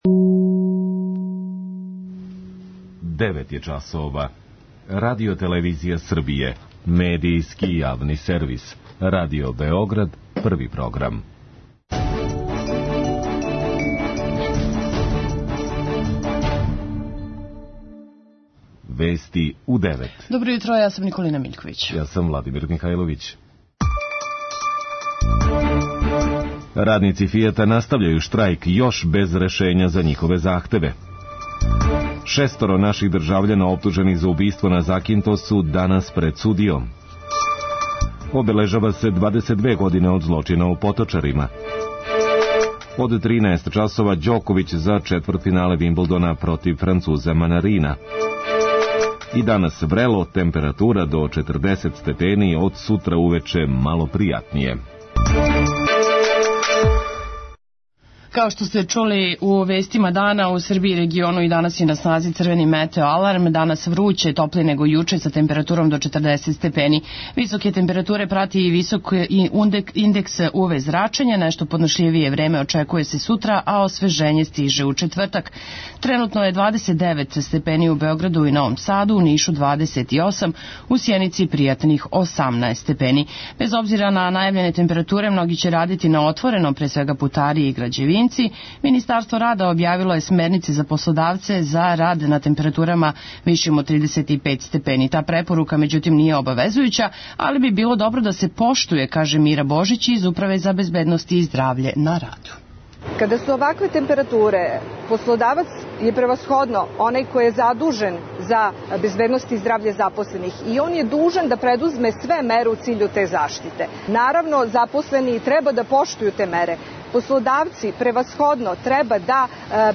преузми : 3.57 MB Вести у 9 Autor: разни аутори Преглед најважнијиx информација из земље из света.